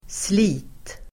Uttal: [sli:t]